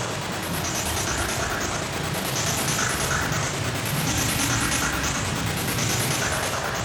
Index of /musicradar/stereo-toolkit-samples/Tempo Loops/140bpm
STK_MovingNoiseE-140_03.wav